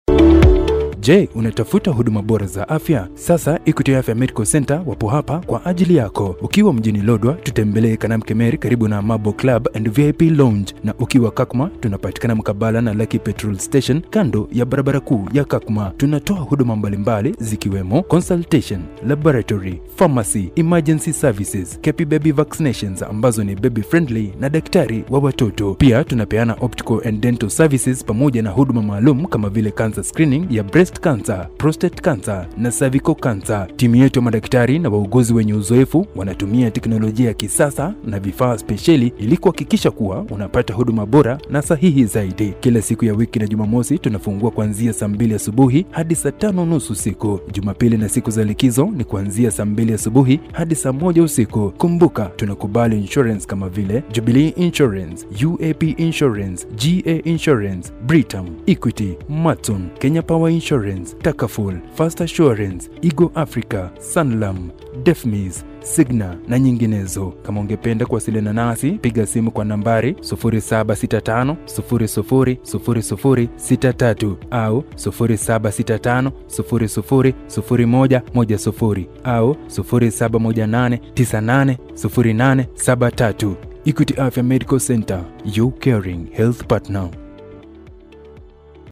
Authoritative Corporate Experienced